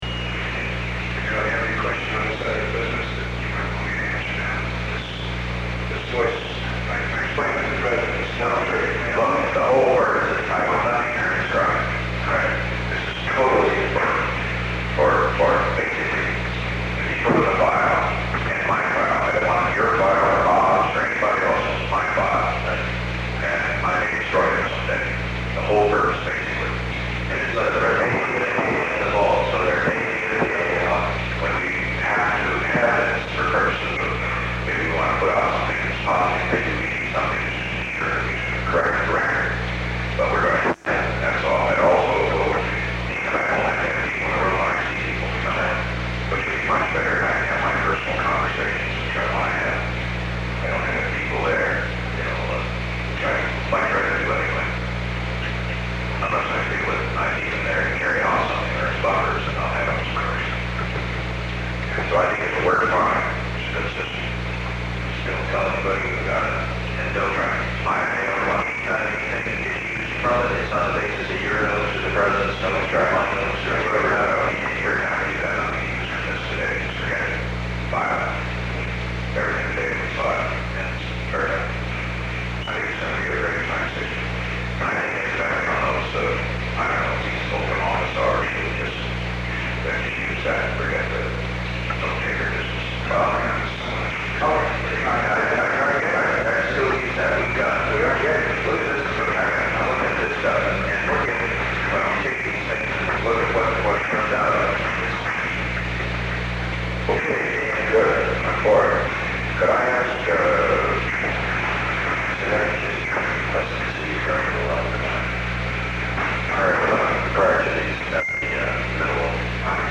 P = President Richard Nixon
HRH = Chief of Staff H.R. "Bob" Haldeman